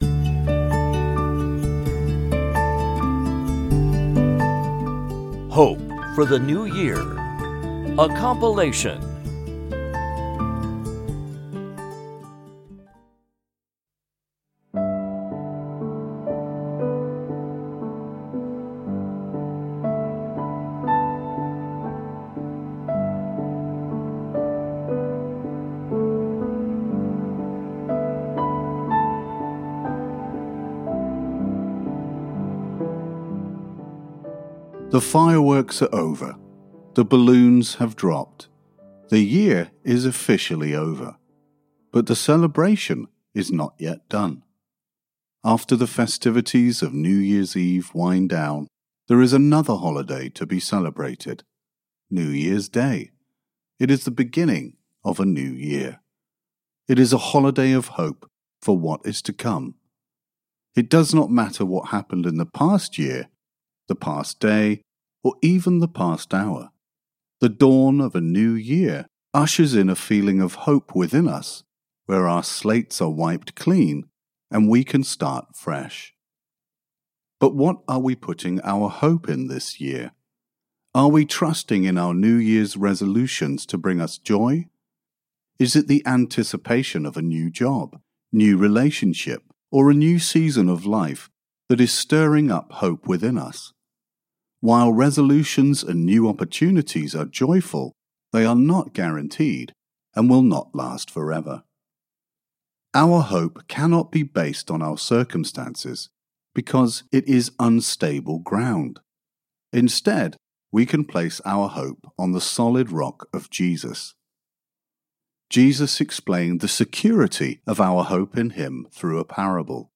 TFI_Devotional_Hope_for_the_New_Year.mp3